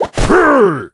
hunter_deploy_02.ogg